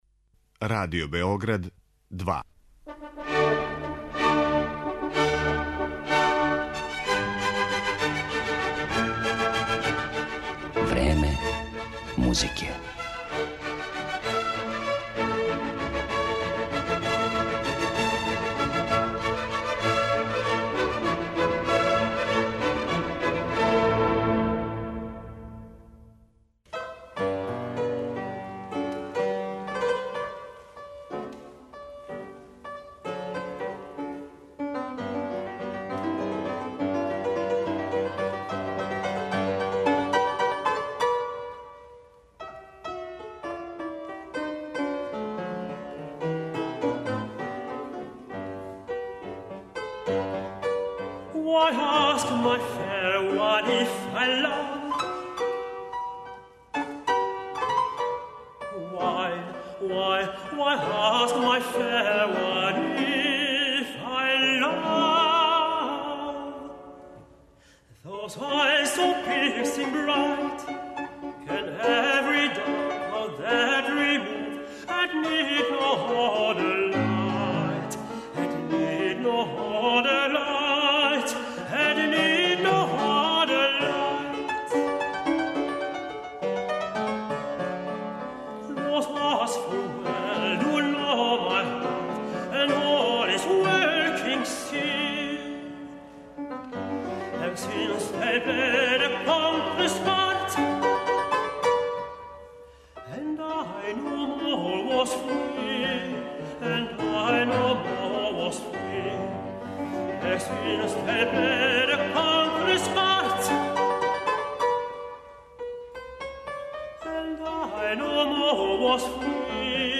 Многима је податак да је Јозеф Хајдн готово читавог живота писао песме за глас и клавир сасвим непознат, јер се овај не тако мали део његове заоставштине и данас ретко проналази на концертним програмима и снимцима.
певаће белгијски тенор
уз бугарског пијанисту